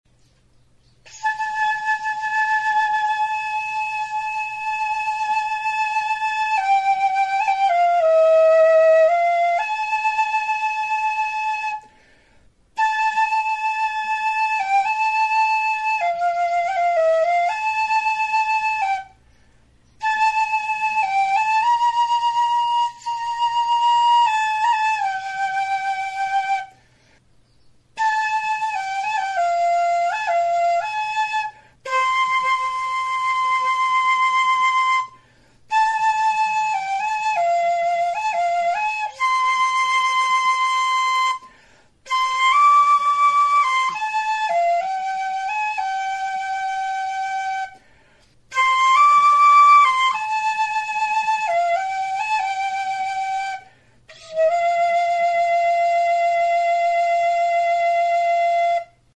Membranophones -> Mirliton
Aerophones -> Flutes -> Transverse flutes
Recorded with this music instrument.
Azkeneko hau, paperezko mintz batekin estaltzen da eta flauta hotsa aldatzen du, 'mirliton' gisa funtzionatuz.